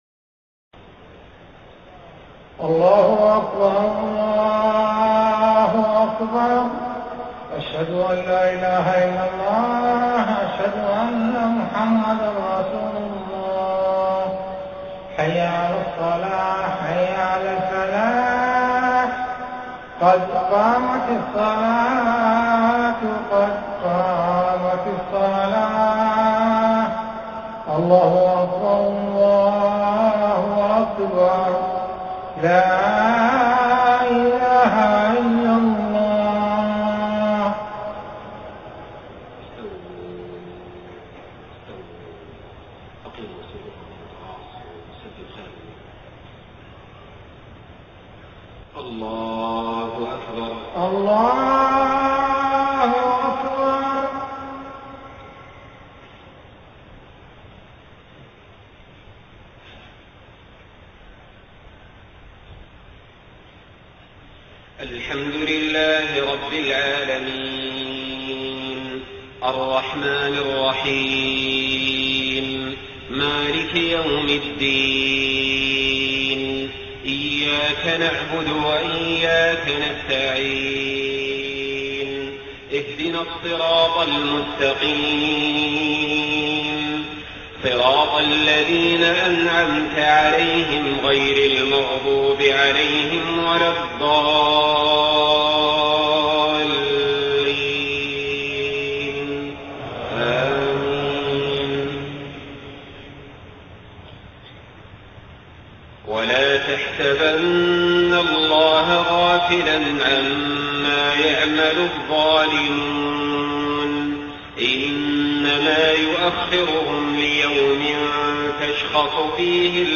صلاة العشاء 9 محرم 1430هـ خواتيم سورة إبراهيم 42-52 > 1430 🕋 > الفروض - تلاوات الحرمين